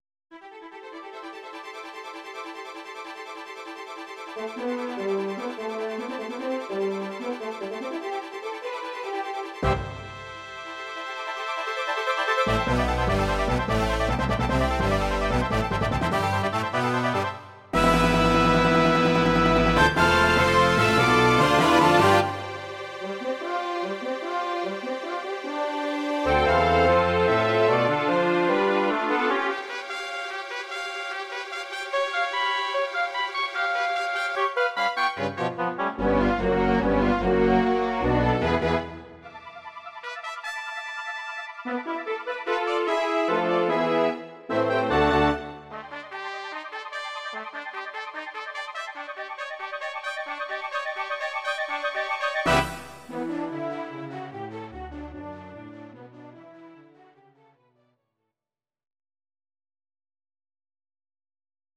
Audio Recordings based on Midi-files
Classical, Instrumental